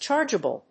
音節charge・a・ble 発音記号・読み方
/tʃάɚdʒəbl(米国英語), tʃάːdʒəbl(英国英語)/